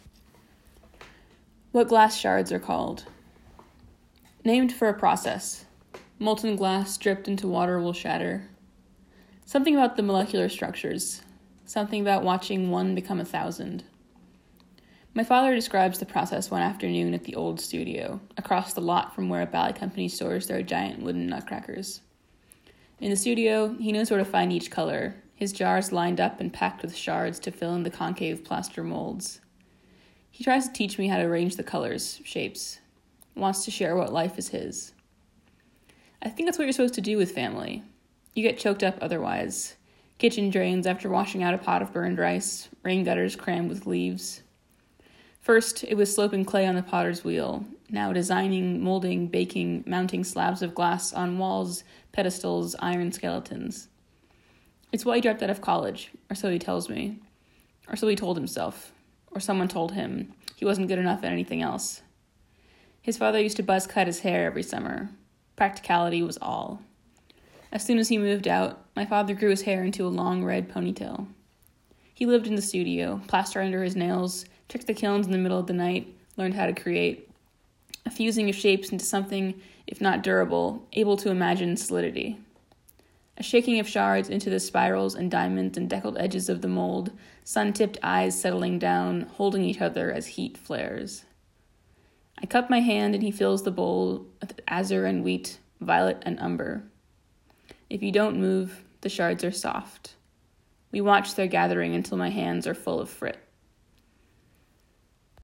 Spoken text of "What Glass Shards Are Called"